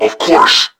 I wanted Volkov & Chitzkoi voices, but wasn't sure what to use, so i decided to take the C&C Remastered RA1 voice lines and triplex them, with some adjustments, to make them cybornetic sounding.